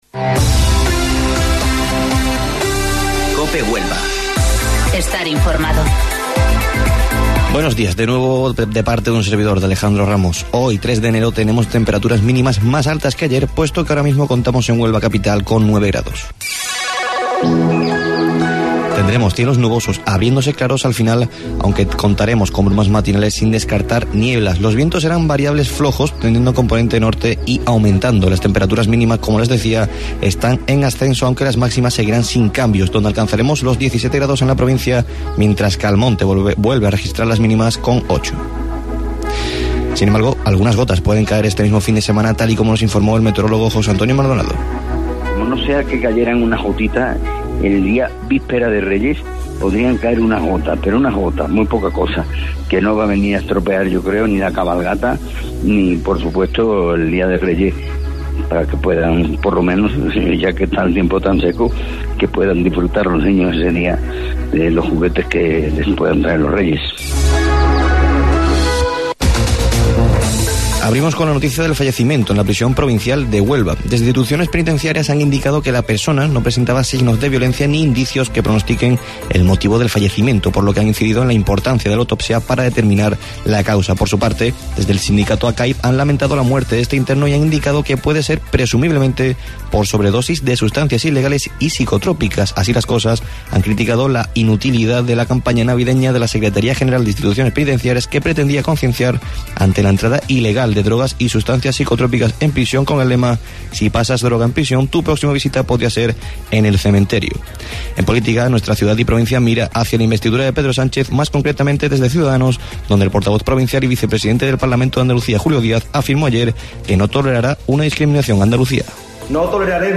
AUDIO: Informativo Local 08:25 del 3 de Enero